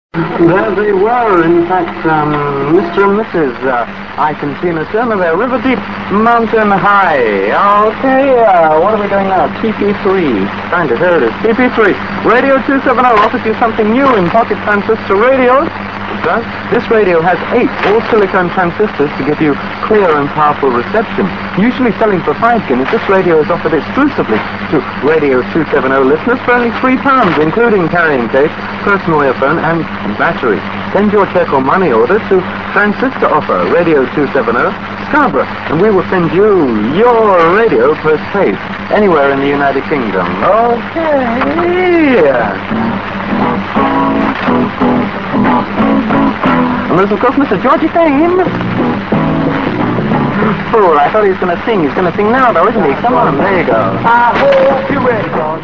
This recording was made, at some distance